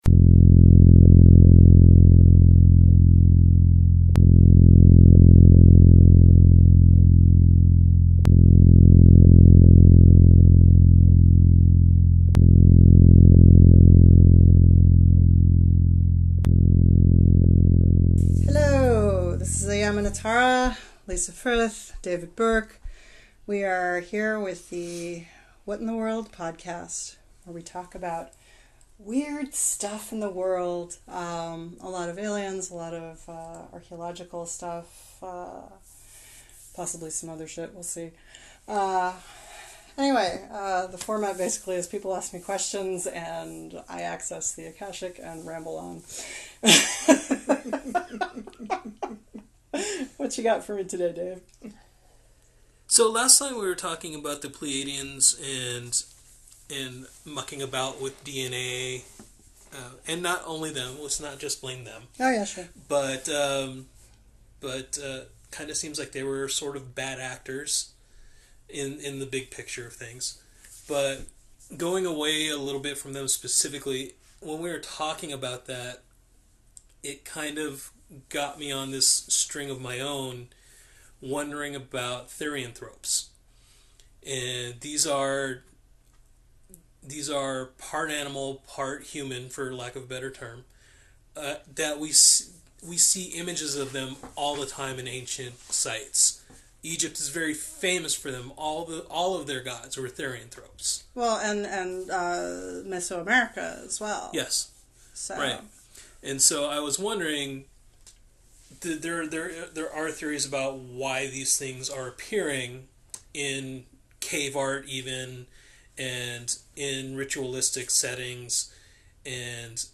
Intro and Outro are a recording of a Keppler star, courtesy of NASA